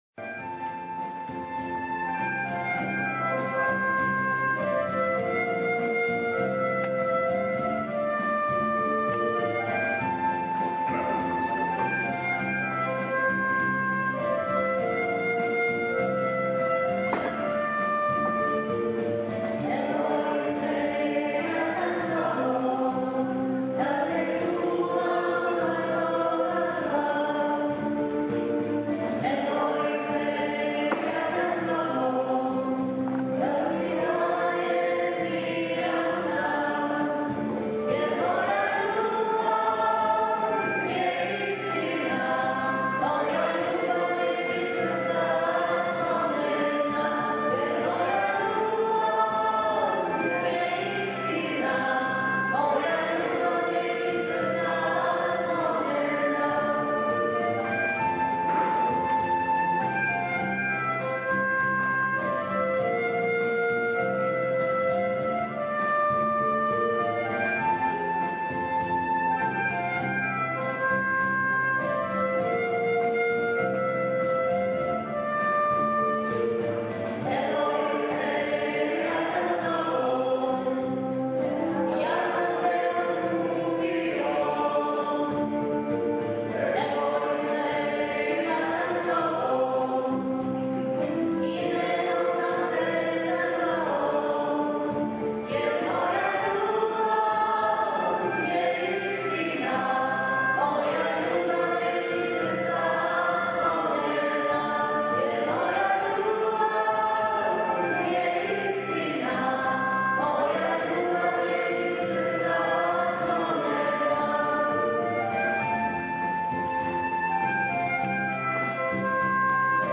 Our choir members singing at mass celebrated by bishop Hurley.